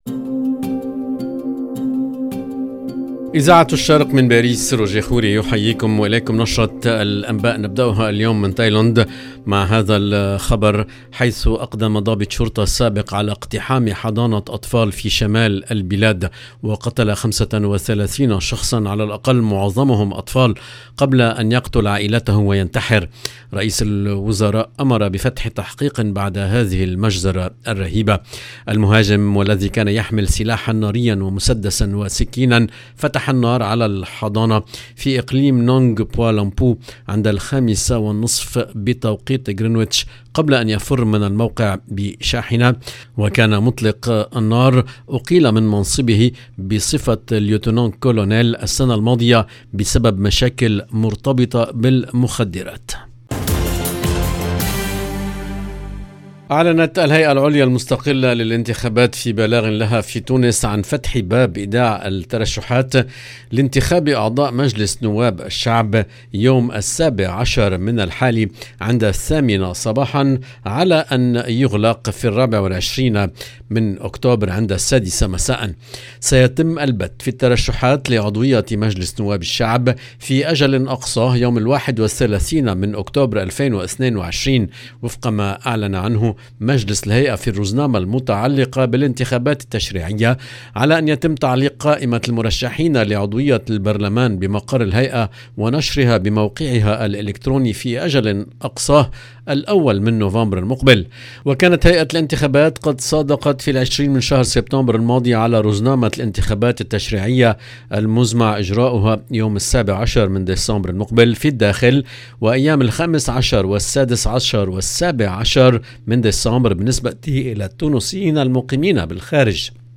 LE JOURNAL EN LANGUE ARABE DU SOIR DU 6/10/22